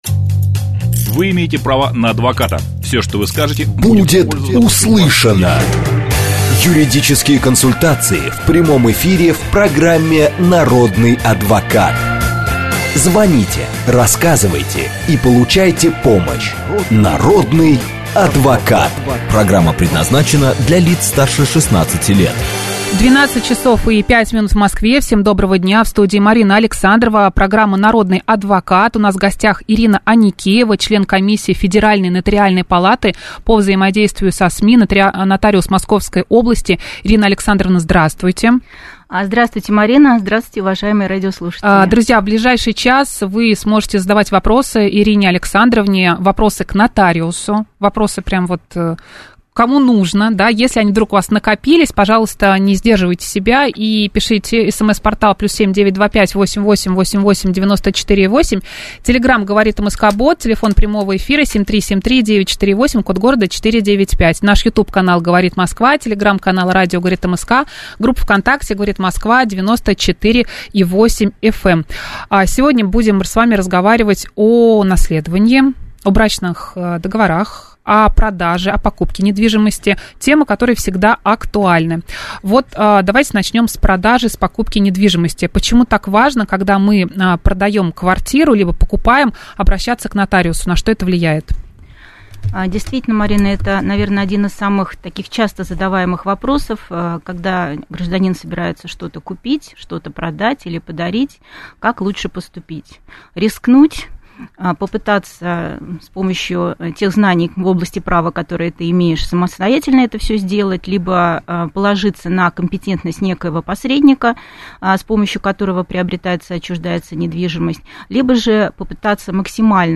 Радиоэфир с нотариусом